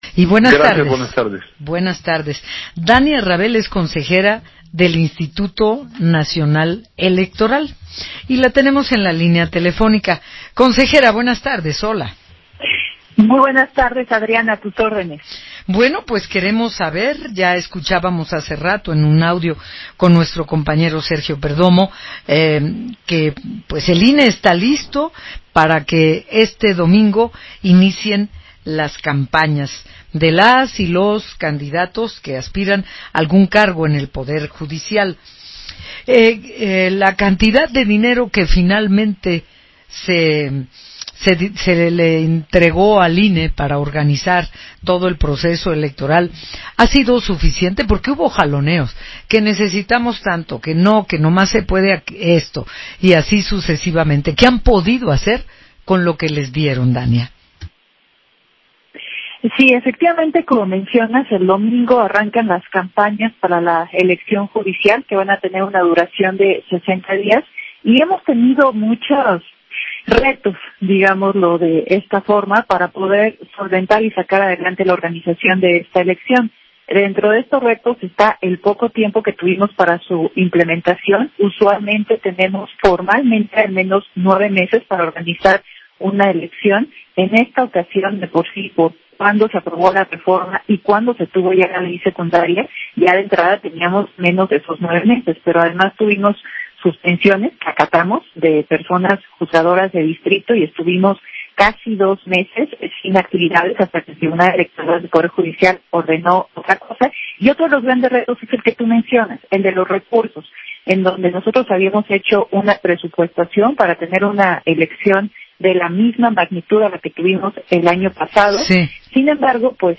Entrevista de la Consejera Electoral Dania Ravel